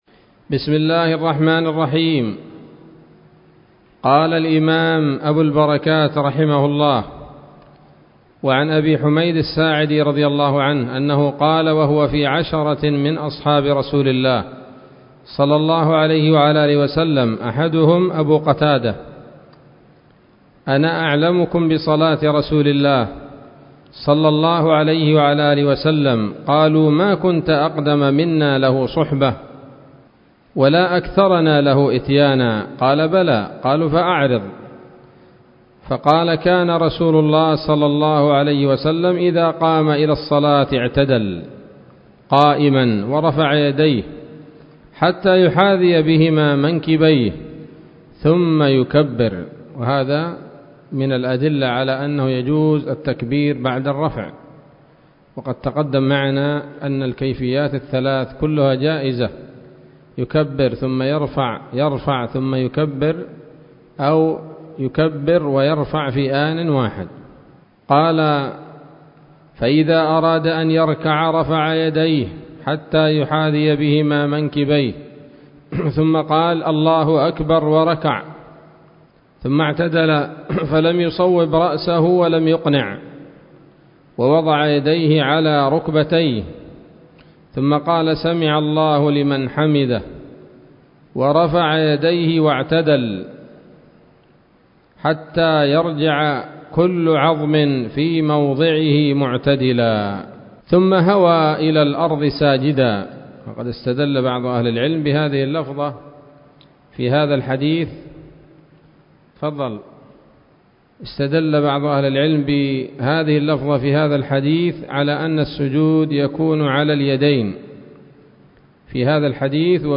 الدرس العاشر من أبواب صفة الصلاة من نيل الأوطار